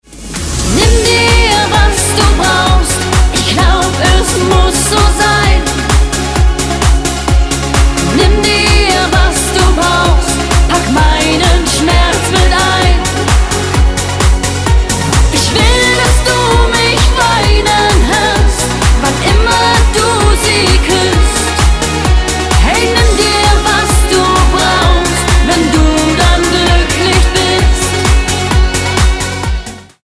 Genre: Schlager.